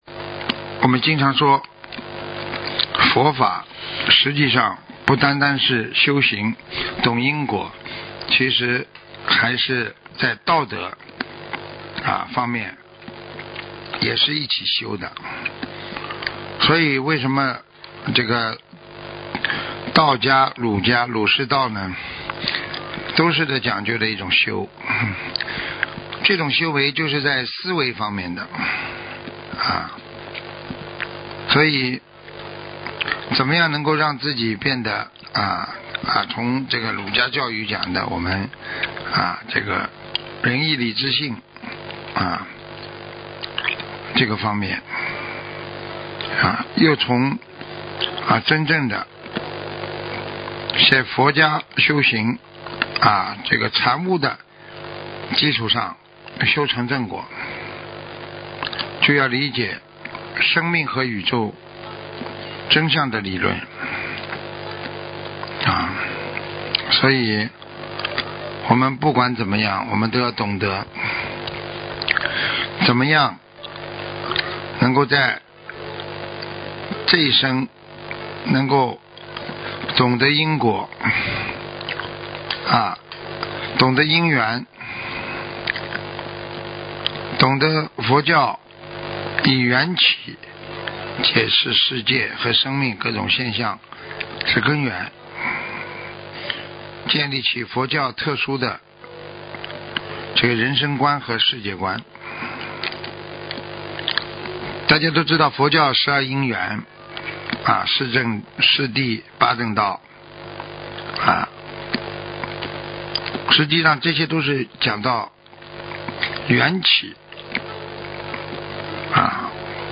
广播讲座